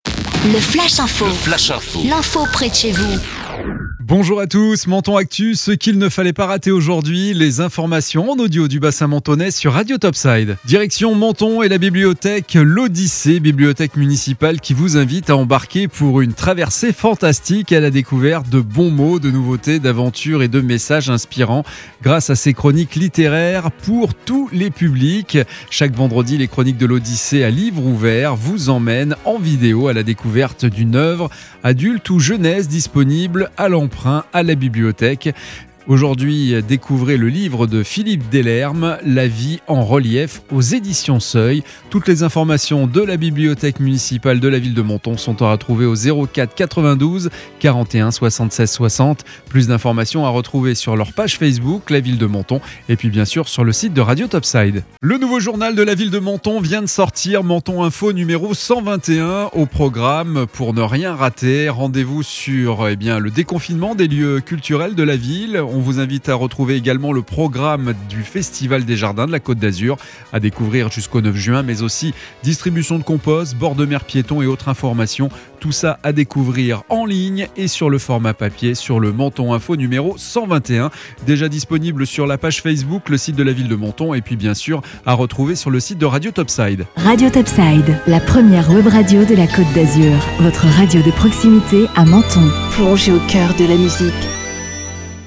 Menton Actu - Le flash info du vendredi 14 mai 2021